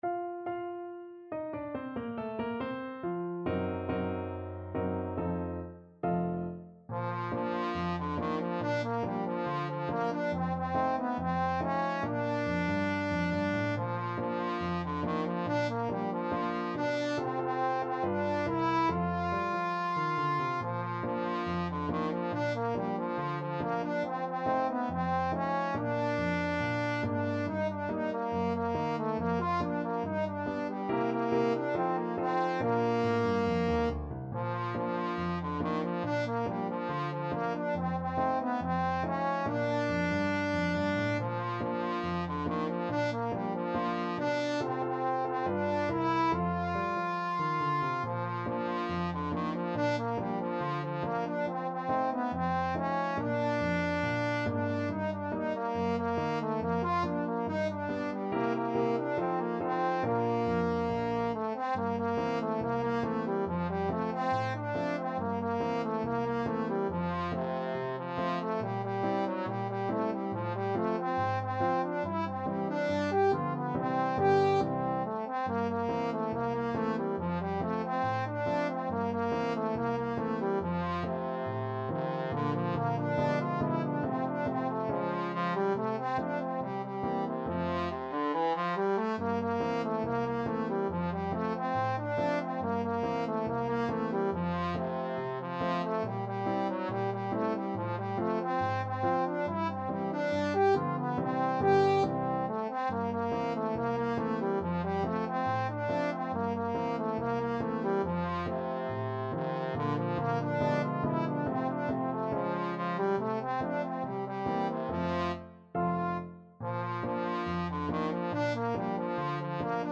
Trombone
Bb major (Sounding Pitch) (View more Bb major Music for Trombone )
Not fast Not fast. = 70
2/4 (View more 2/4 Music)
Jazz (View more Jazz Trombone Music)